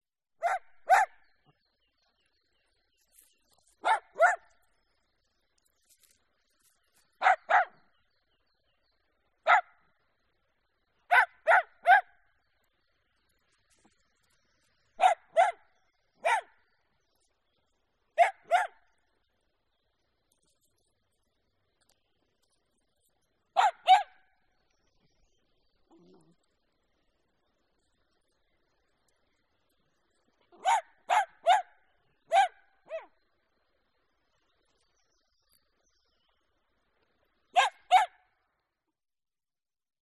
دانلود صدای توله سگ دوستداشتنی از ساعد نیوز با لینک مستقیم و کیفیت بالا
جلوه های صوتی
برچسب: دانلود آهنگ های افکت صوتی انسان و موجودات زنده دانلود آلبوم صدای سگ از افکت صوتی انسان و موجودات زنده